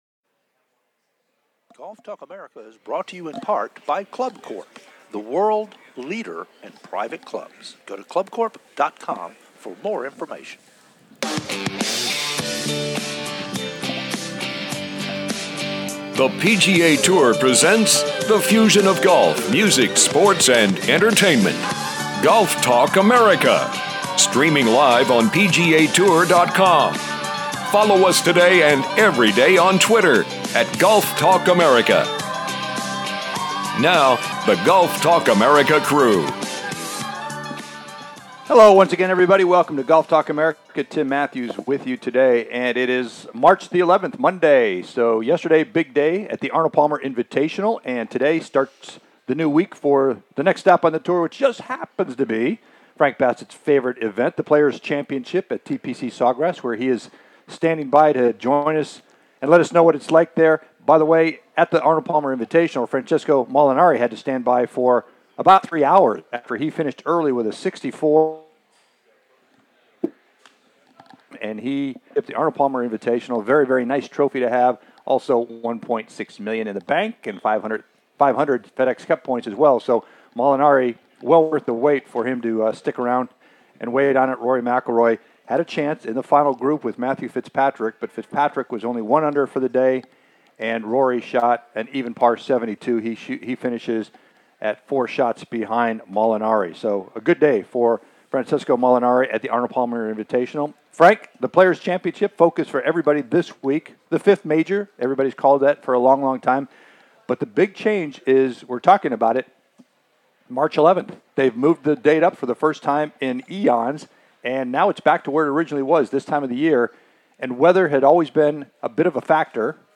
"LIVE" From The Players Championship
"LIVE" from The Marriott Sawgrass at The Players Championship